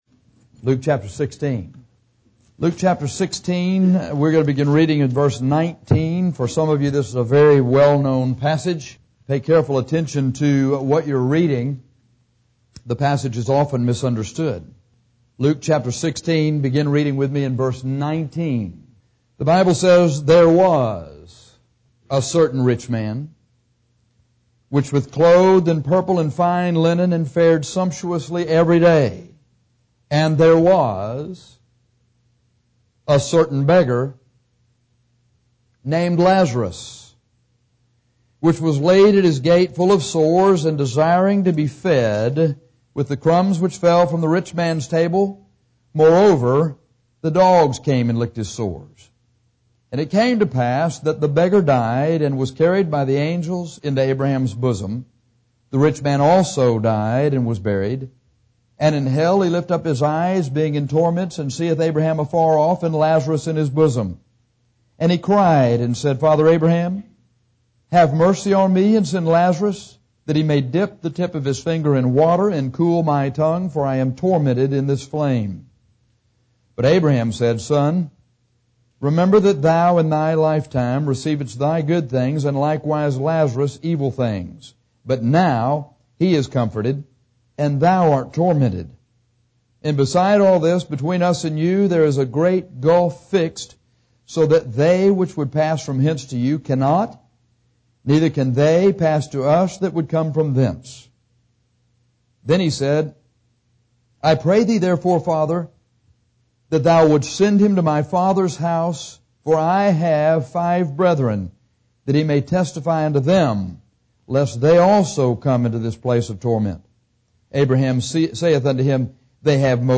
This is a powerful and yet very simple sermon on hell.